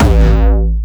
Jumpstyle Kick 10
5 A1.wav